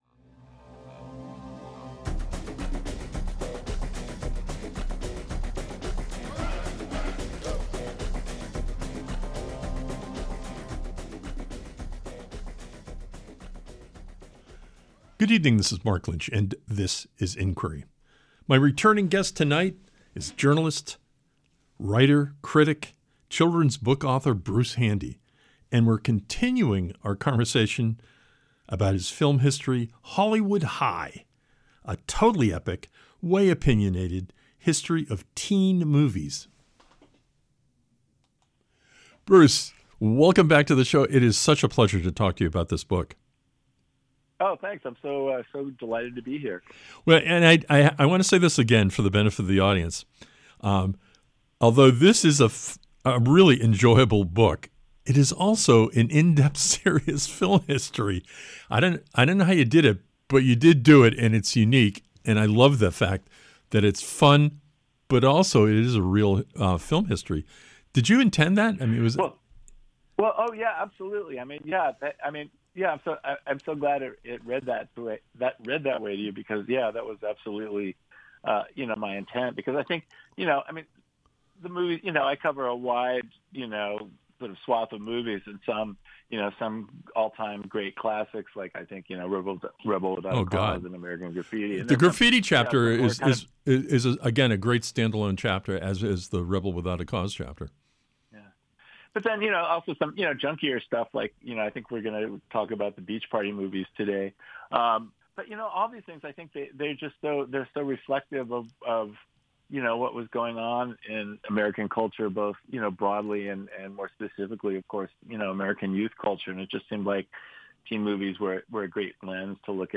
We talk again with BRUCE HANDY, journalist, critic, humorist, and children’s book author, about his book: HOLLYWOOD HIGH: A TOTALLY EPIC WAY OPINIONATED HISTORY OF TEEN MOVIES. Tonight, we look at Frankie and Annette and their “beach” movies.